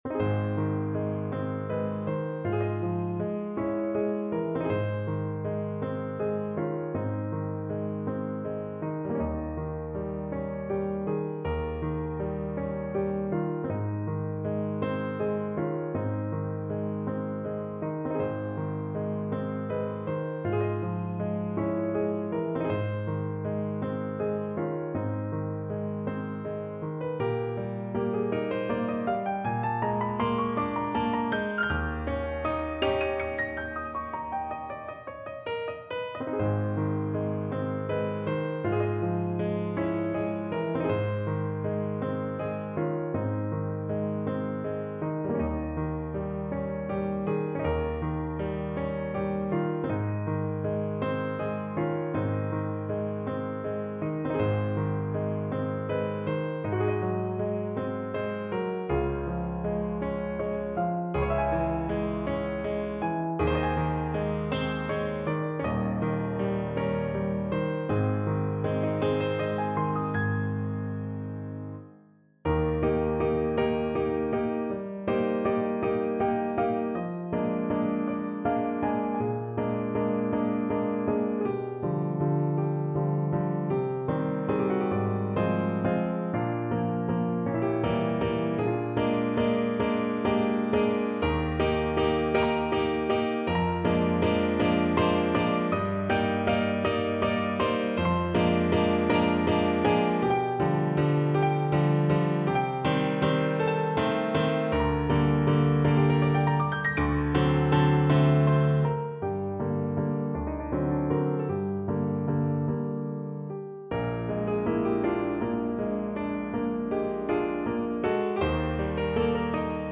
Free Sheet music for Piano
No parts available for this pieces as it is for solo piano.
Ab major (Sounding Pitch) (View more Ab major Music for Piano )
~ = 100 Andante espressivo
12/8 (View more 12/8 Music)
Instrument:
Classical (View more Classical Piano Music)